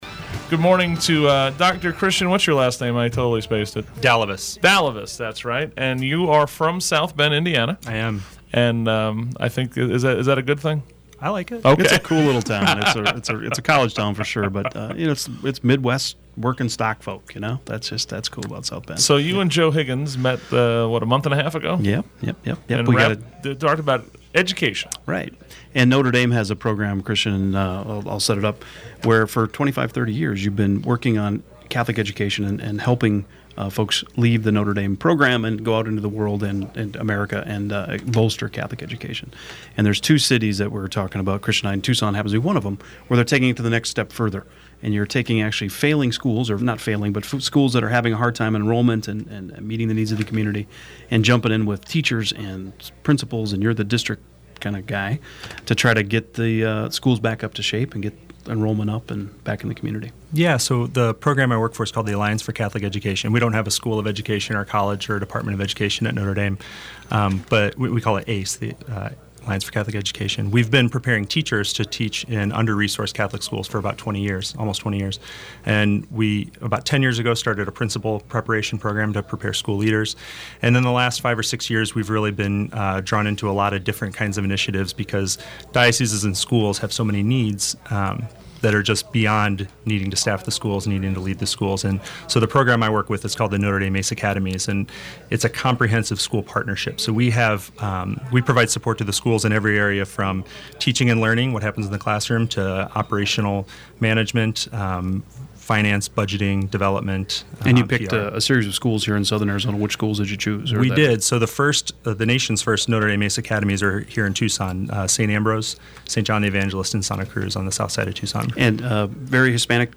Hear the interview, in which he outlined the successes being experienced at the schools.